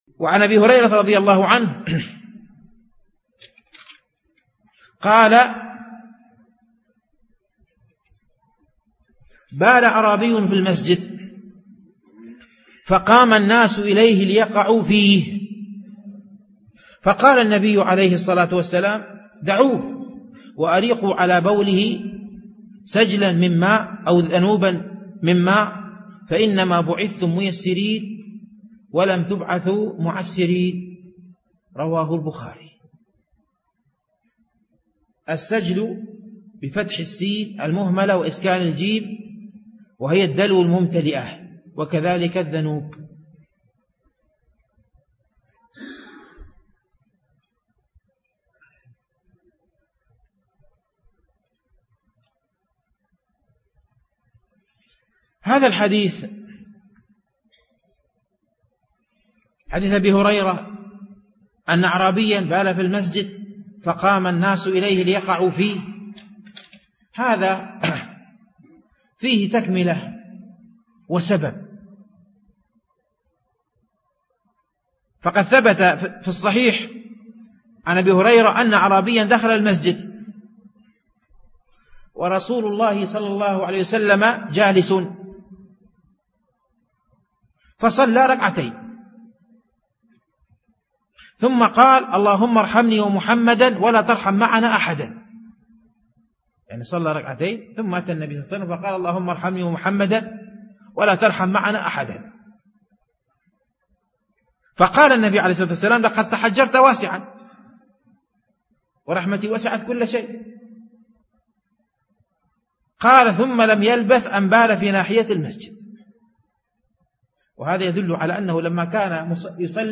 الألبوم: شبكة بينونة للعلوم الشرعية المدة: 19:48 دقائق (4.56 م.بايت) التنسيق: MP3 Mono 22kHz 32Kbps (VBR)